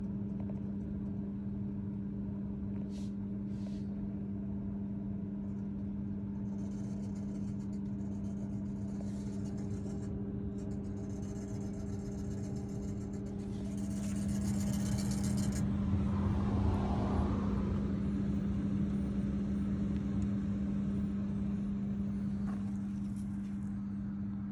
Pour le bruit, ça ressemble à un bruit de compresseur.